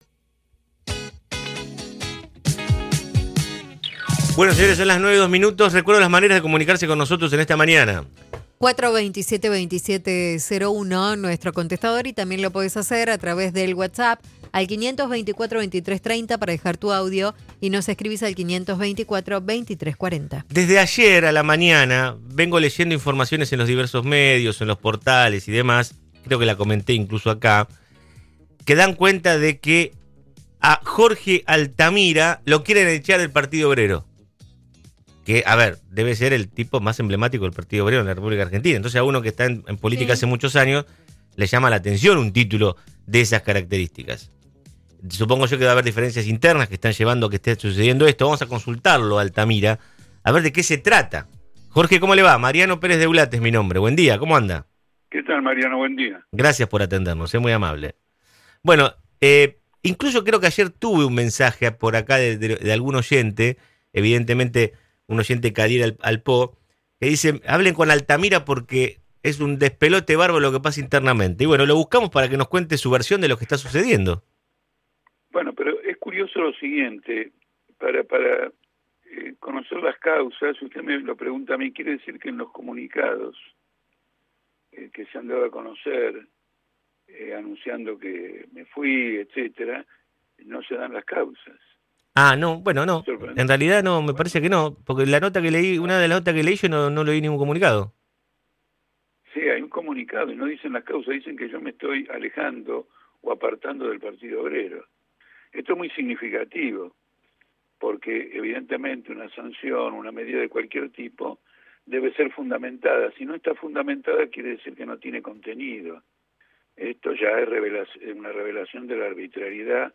En medio del clima de tensión que se vive en el Partido Obrero por una serie de acusaciones cruzadas que involucra a distintos dirigentes, Jorge Altamira, uno de los protagonistas de la contienda, brindó una entrevista con La Redonda 100.3 y se refirió a las complicaciones que se han generado y fijó posición con respecto a la crisis que atraviesa el espacio.